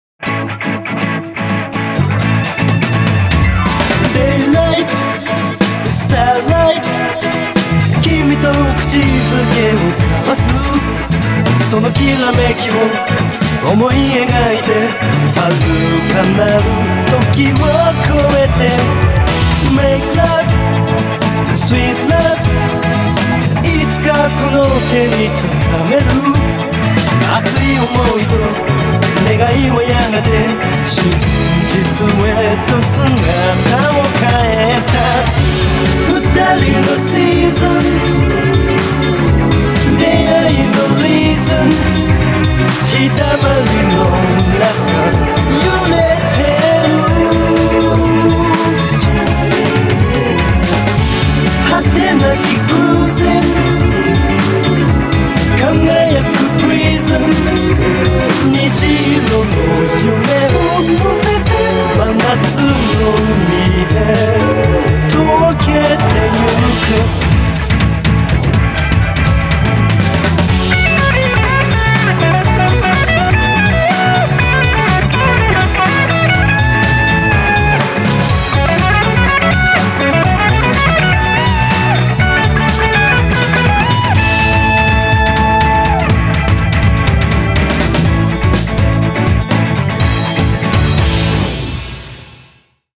BPM123
Audio QualityPerfect (High Quality)
A guitar pop song with 20th swing notes.